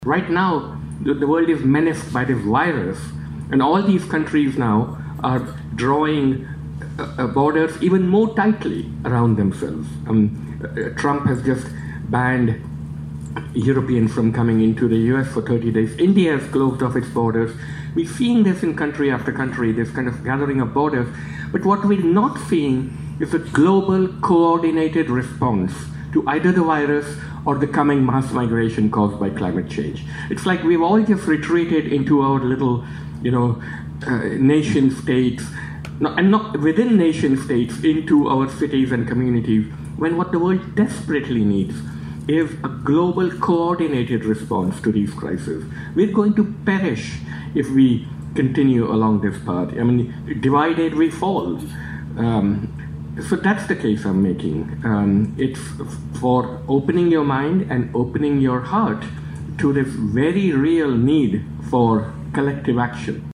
LISTEN: Suketu Mehta speaks on COVID-19 and its effects on borders at the Woordfees event To Lose Everything: Three International Authors, on March 12.
Mehta-at-Woordfees.mp3